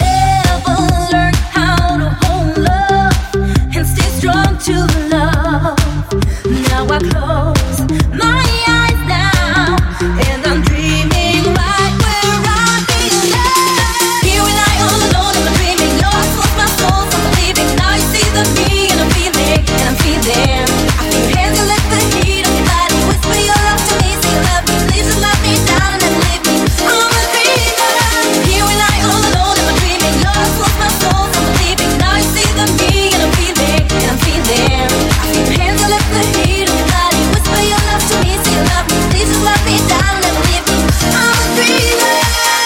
Genere: deep house, tropical, house, club, edm, remix